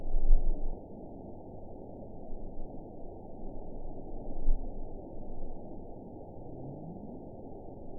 event 912683 date 03/31/22 time 17:13:54 GMT (3 years, 1 month ago) score 9.54 location TSS-AB03 detected by nrw target species NRW annotations +NRW Spectrogram: Frequency (kHz) vs. Time (s) audio not available .wav